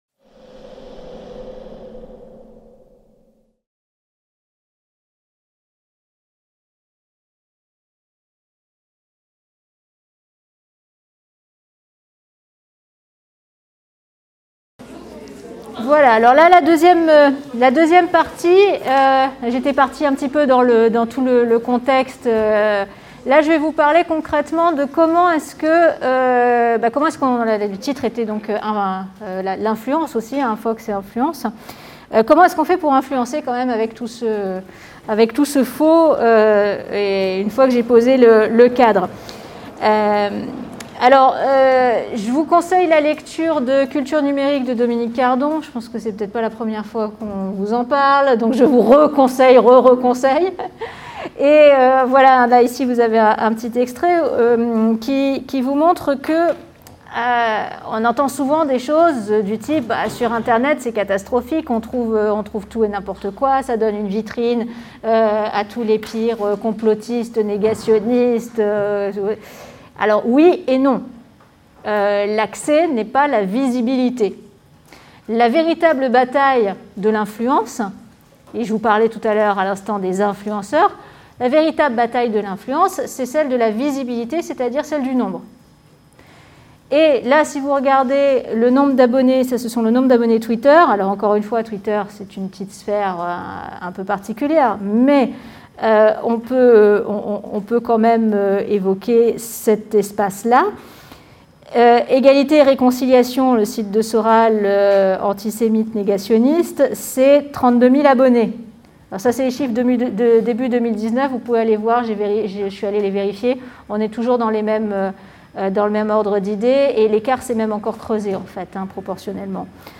Cours de Culture numérique dans le cadre de la Licence Humanités parcours Humanités numériques.